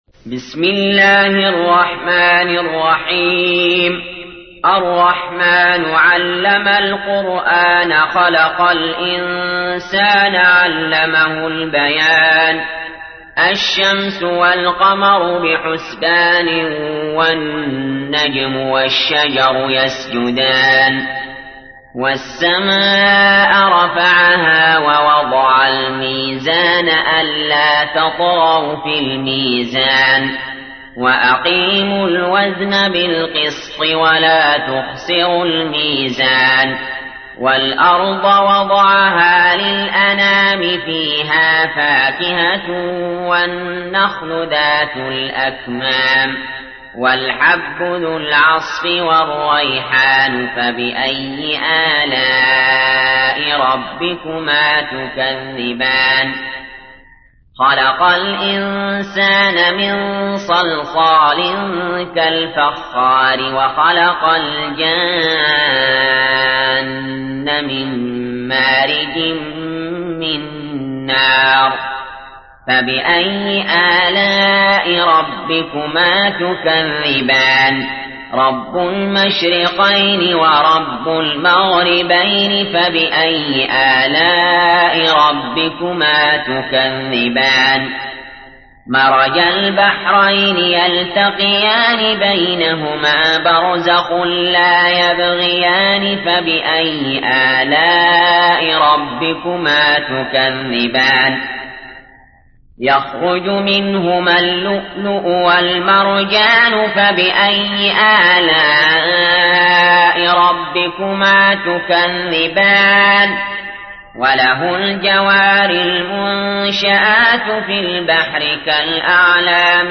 تحميل : 55. سورة الرحمن / القارئ علي جابر / القرآن الكريم / موقع يا حسين